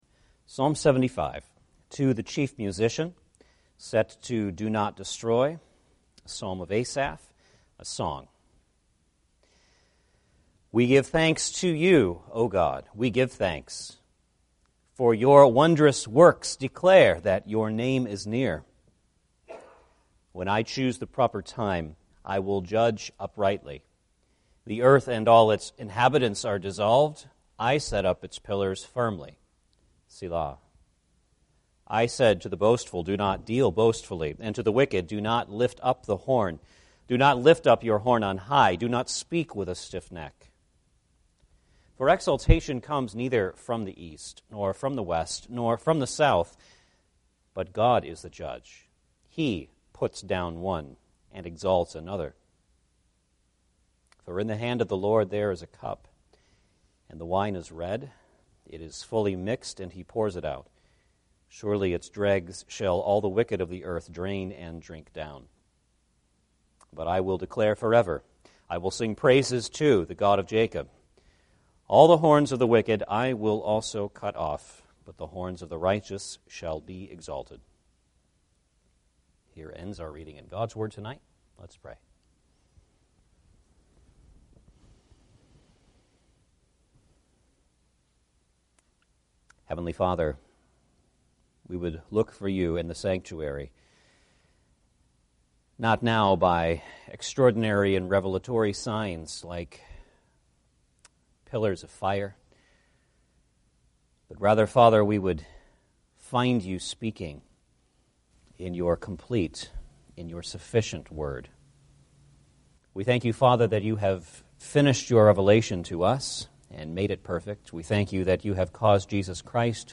Passage: Psalm 75 Service Type: Sunday Evening Service « Ascending with Uplifted Hands Why I Love the OPC